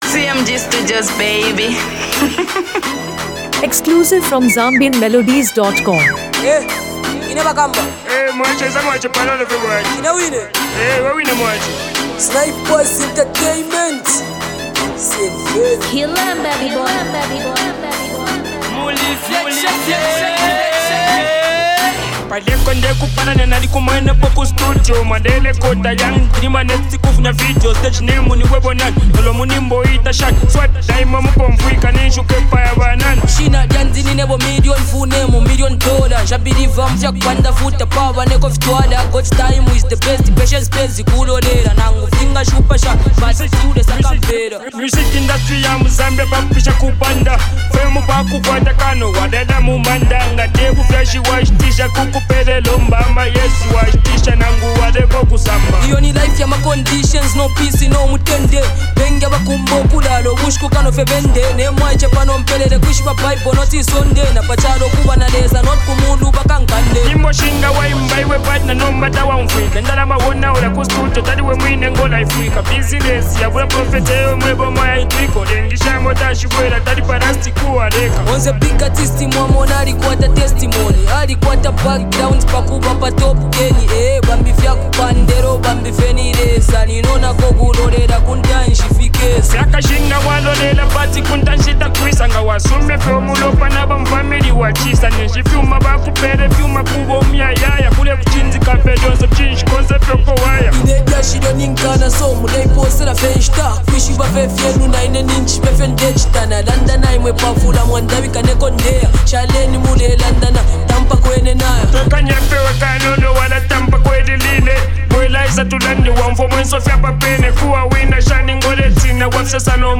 blends Afro-fusion and contemporary Zambian hip-hop
With a raw yet polished delivery
smooth flow, and high-quality sound.